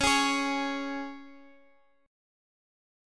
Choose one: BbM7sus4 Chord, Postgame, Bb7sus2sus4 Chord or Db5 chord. Db5 chord